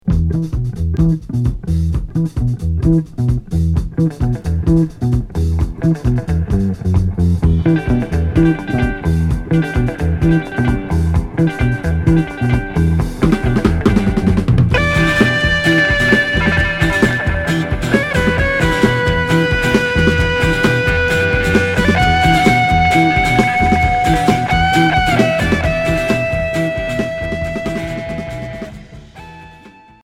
Groove rock psychédélique Unique 45t retour à l'accueil